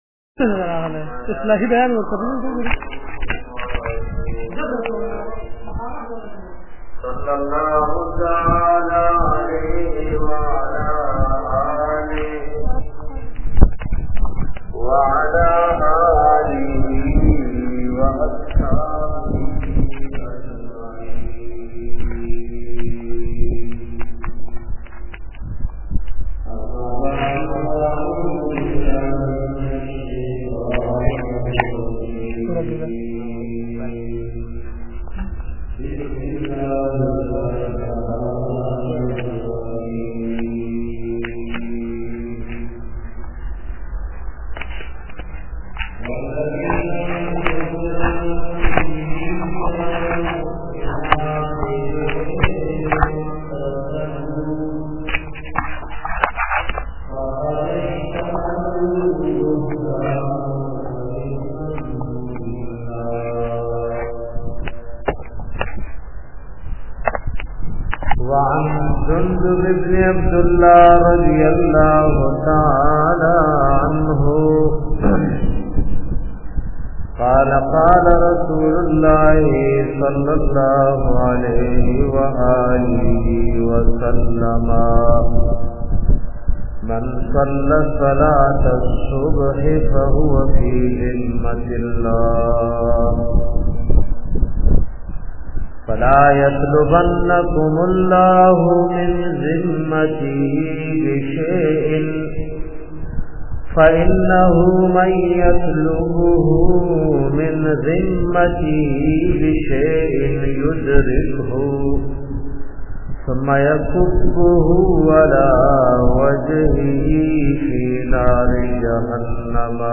ajj ka jumma bayan da garib ao muflis fazilat